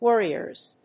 /ˈwɝiɝz(米国英語), ˈwɜ:i:ɜ:z(英国英語)/